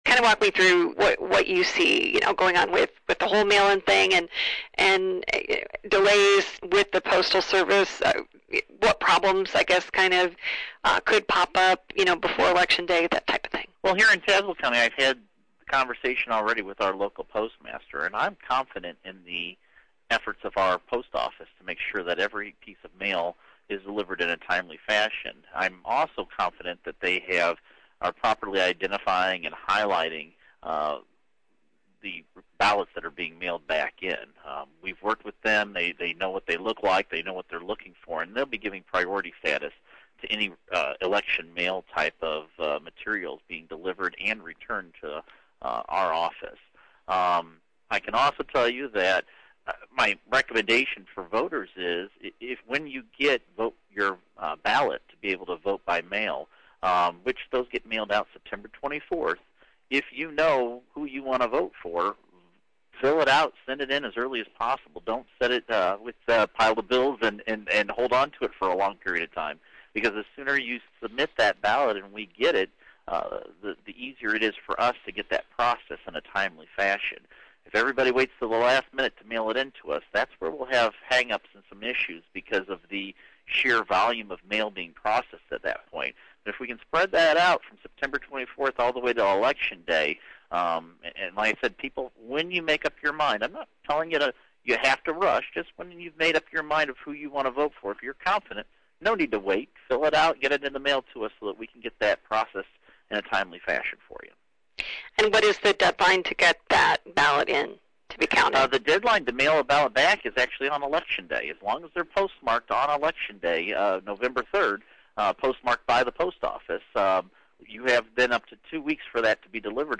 Full interview with Ackerman:
ackerman-mail-in-0901.mp3